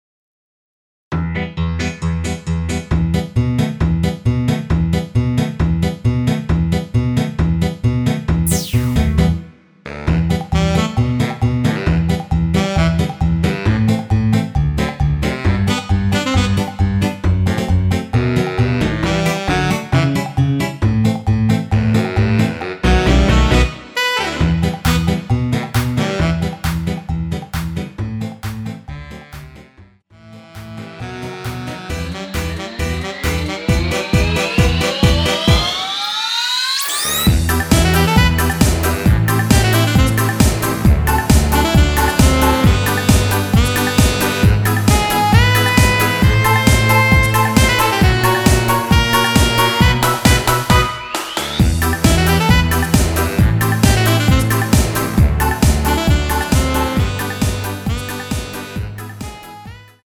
원키에서(-2)내린 MR입니다.
Em
앞부분30초, 뒷부분30초씩 편집해서 올려 드리고 있습니다.
중간에 음이 끈어지고 다시 나오는 이유는